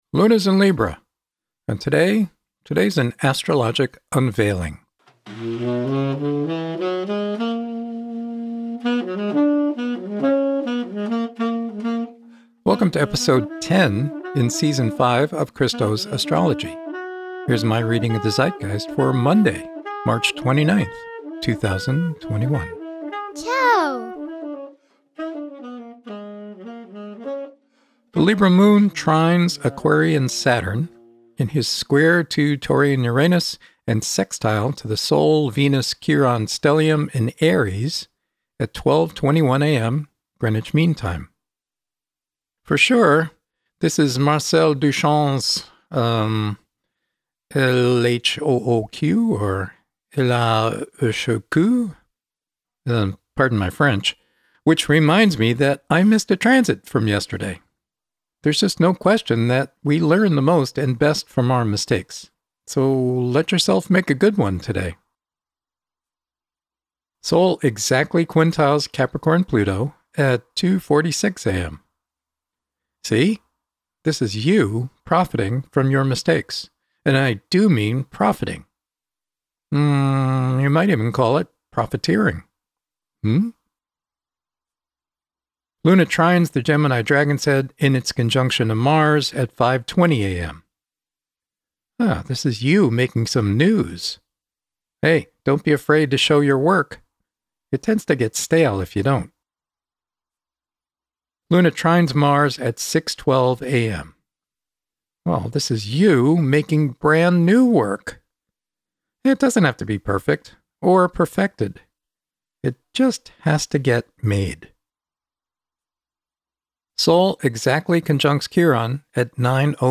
Music and Sound credits: